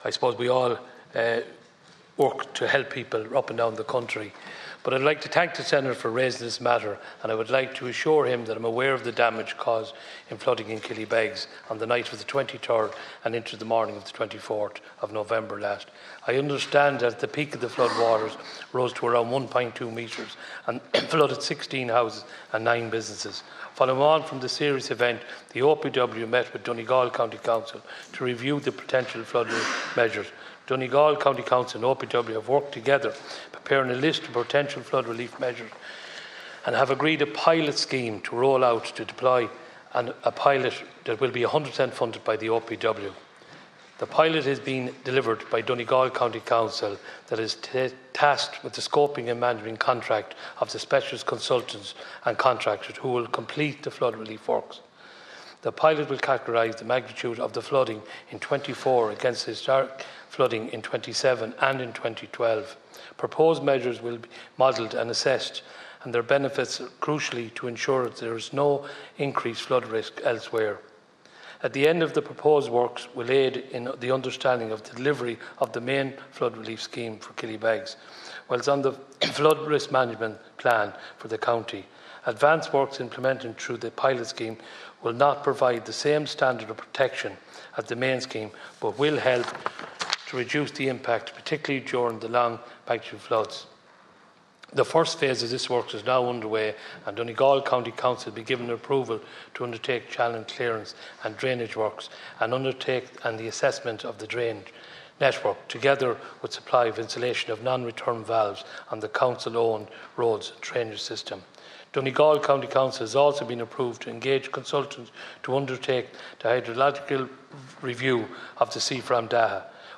Killybegs flooding raised in Seanad
In response, Minister Kevin Boxer Moran said a pilot project is underway with the first phase being carried out……………….